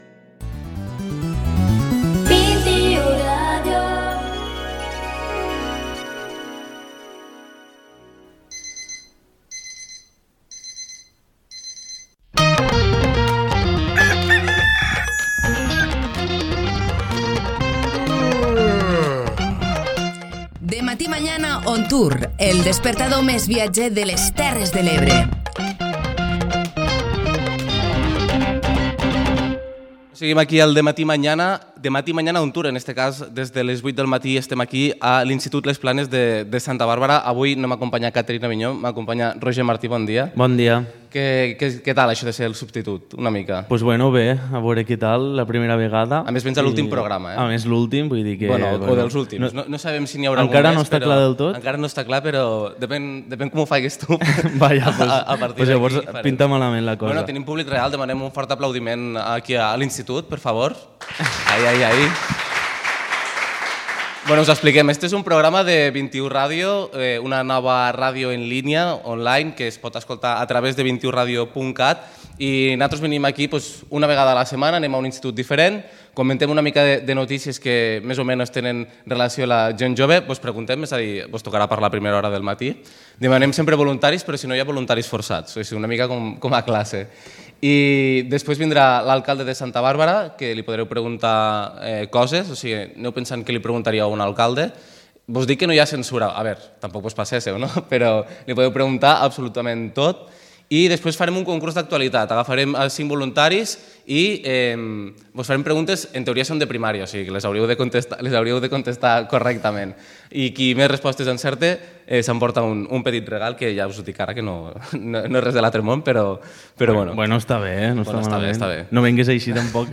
Avui hem matinat de valent per dirigir-nos a Les Planes de Santa Barbara!